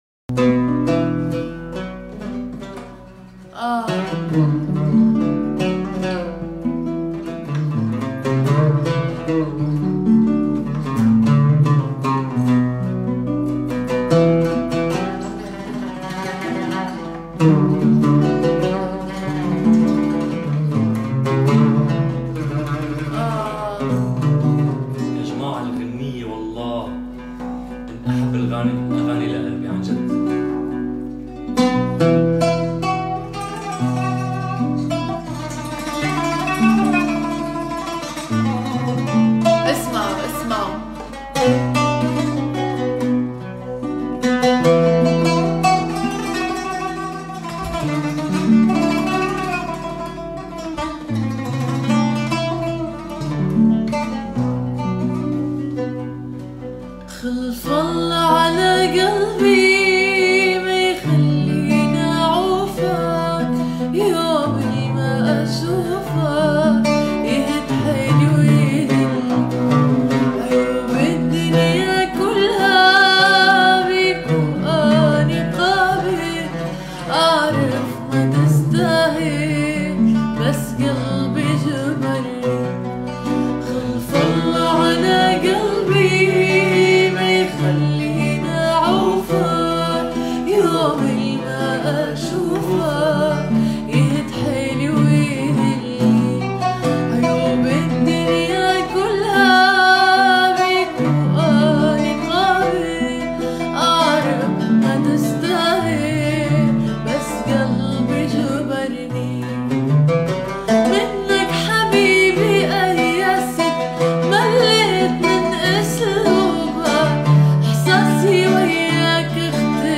با صدای زن